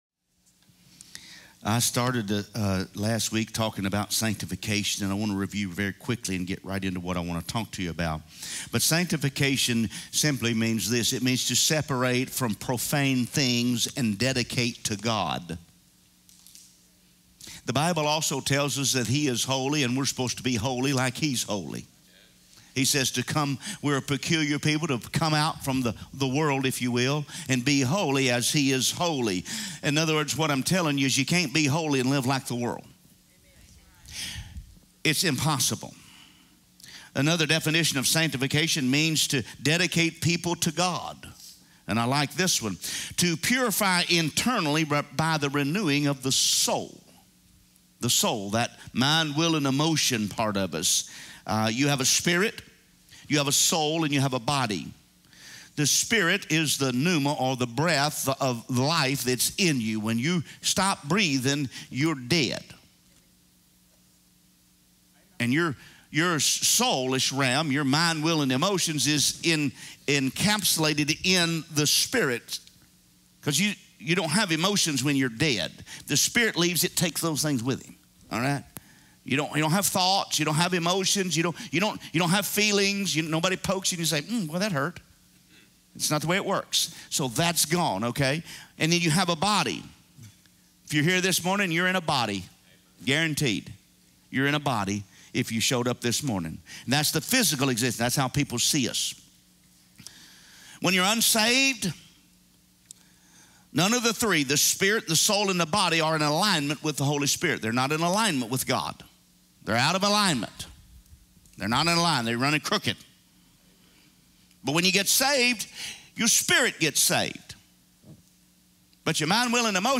A message from the series "Sunday Message."